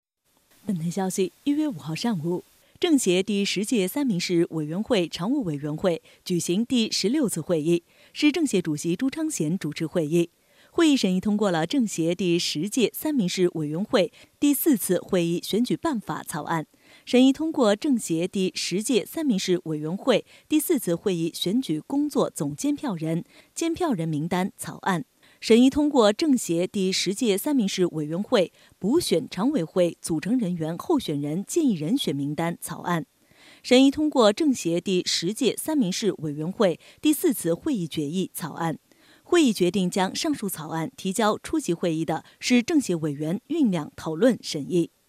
政协十届三明市委员会常务委员会举行第16次会议 _ 音频播报 _ 三明市政协